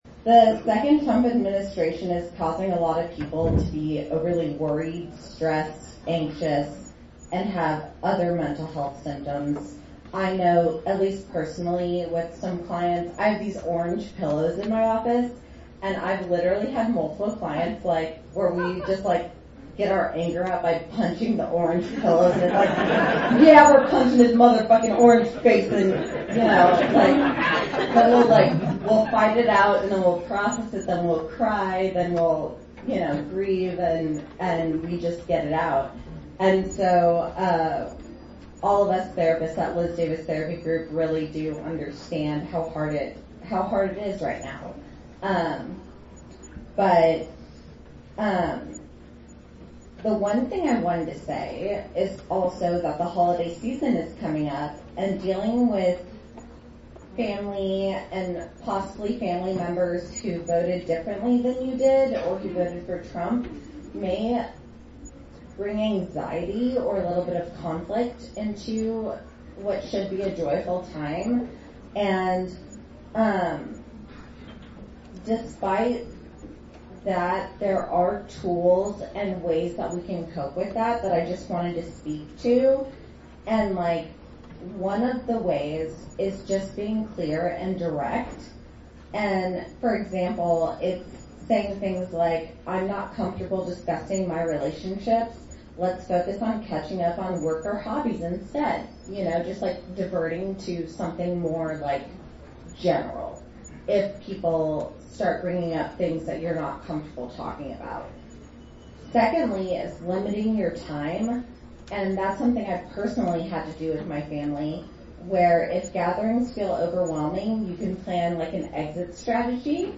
Sunday night in Kansas City the LGBTQIA+ community in Kansas City, Missouri held a Townhall in a supportive establishment on Main Street. More than 150 people attended the over two-hour standing room only event.